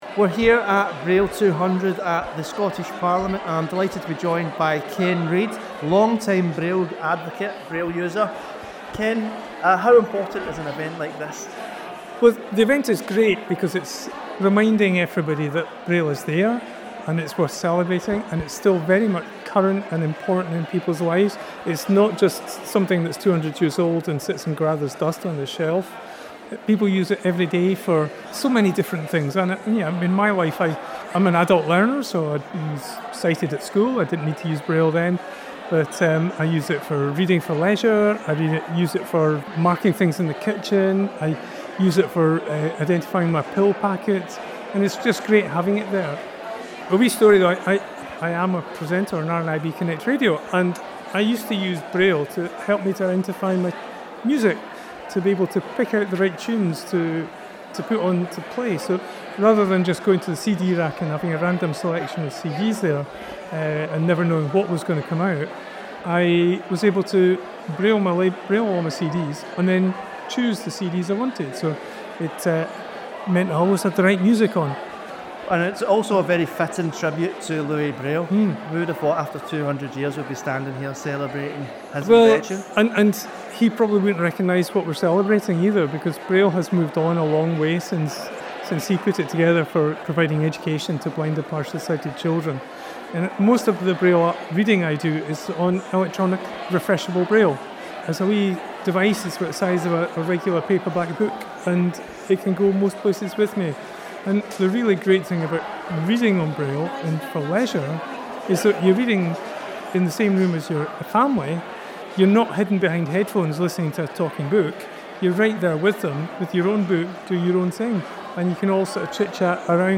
RNIB recently held an event to celebrate the bicentenary of the invention of braille. The Scottish Parliament reception, organised by RNIB Scotland, marked 200 years of the tactile code which enables blind and partially sighted people to read and write.